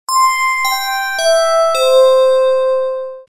Звучный сигнал на СМС - звонок